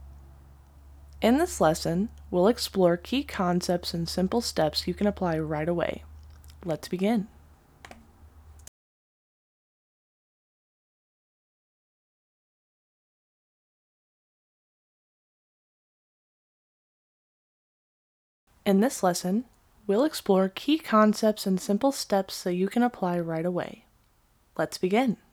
Professional Voiceover Artist
Clear, confident, and high-quality voiceovers for brands, content creators, and businesses.
Clear, articulate, and engaging voices for educational content that keeps learners focused.